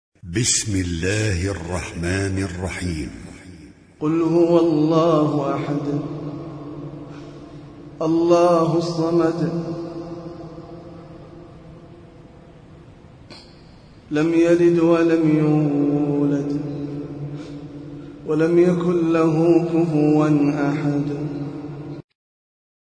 Sûrat Al-Ikhlas (Sincerity) - Al-Mus'haf Al-Murattal
high quality